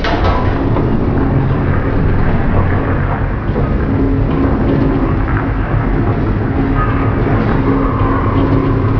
sound / doors